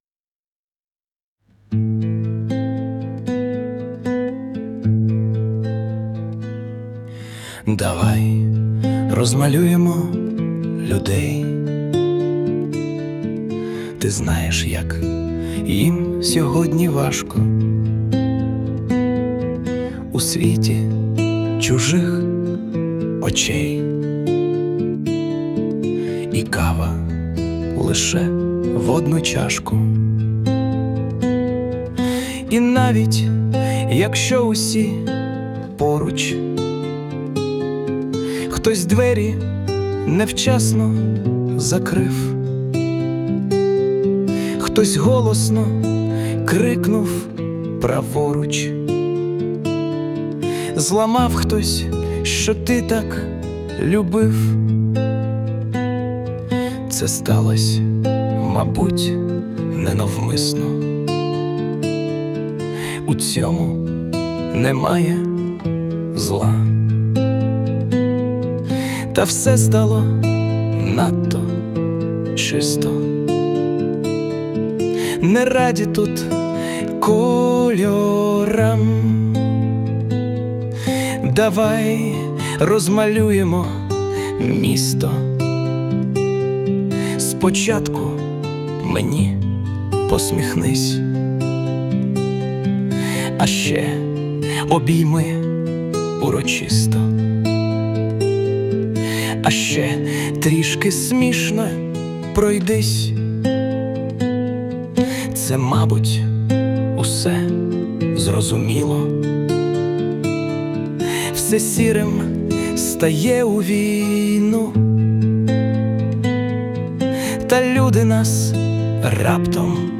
Власне виконання
СТИЛЬОВІ ЖАНРИ: Ліричний
12 12 16 Чудове виконання! 16 У плавному вальсі,закривши очі, сприймати цей світ і все ж надію не втрачати!Хай радість і гарні новини втішають всіх людей! 39 39 39 flo26 flo36
але перепросився з гітарою ... сказав: вибач, що без тебе ... 16
все ж це авторська пісня ...